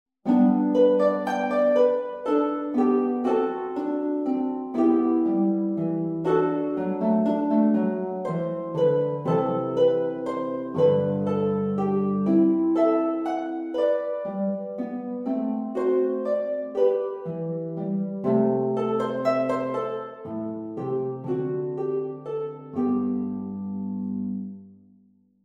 Lever and Pedal Harp.